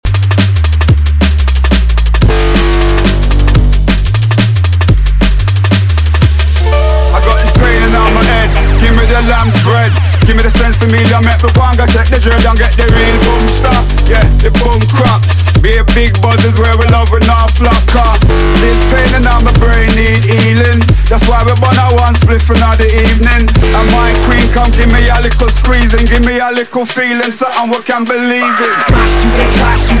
TOP >Vinyl >Drum & Bass / Jungle
Vocal Mix